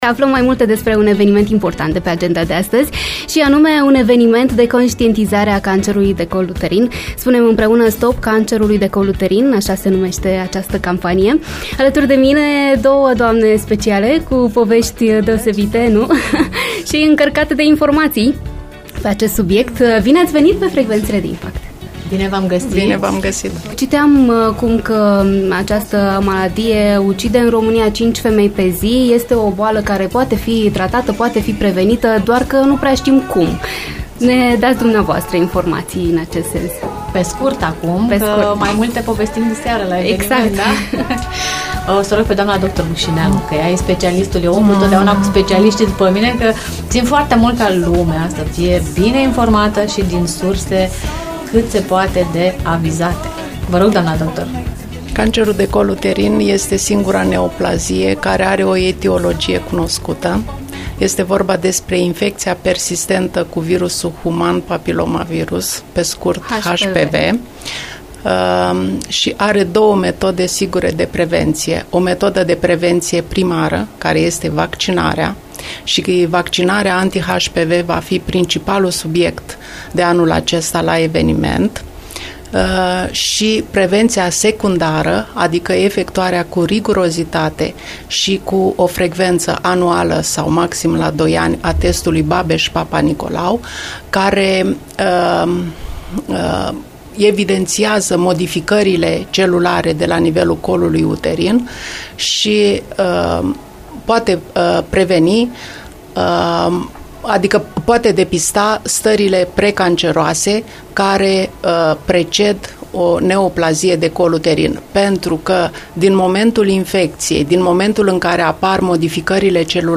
Home Emisiuni After Morning IMPACT FM Suceava susține campania „Oprește cancerul de col uterin !”